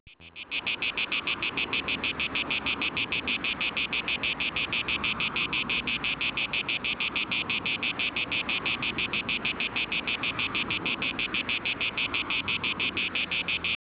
Ecco un elemento molto intonata di  un'orchestra fracassona e sinonimo di CALDO   birba .. quasi non ti notavo e allora vai con gli scatti, un vero proprio servizio fotografico  e  canta pure!